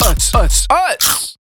fun, catchy sonic logo